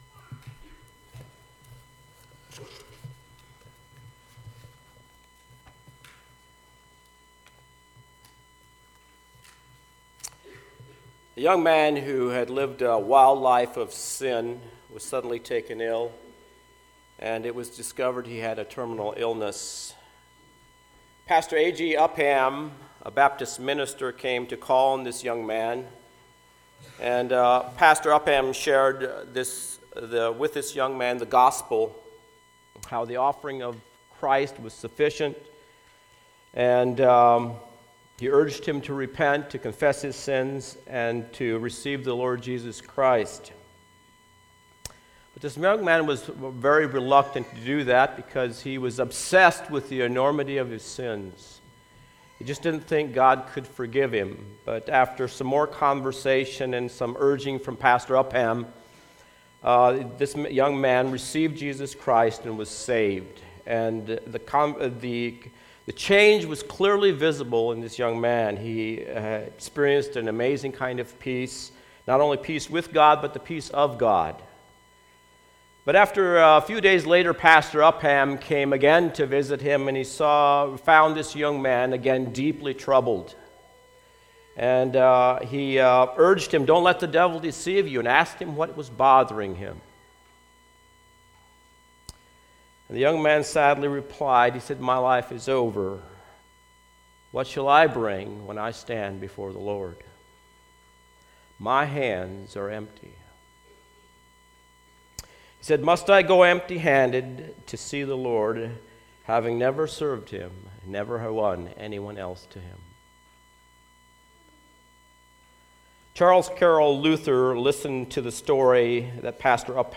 Home Sermons The Epistle of James Can Your Faith Save You?